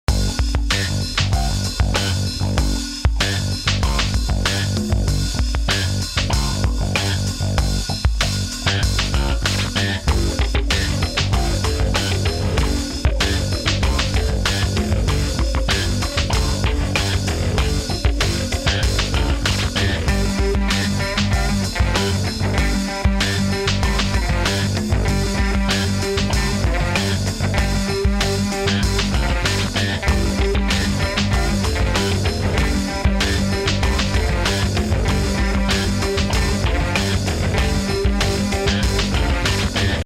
home of the daily improvised booty and machines -
3 bass grooves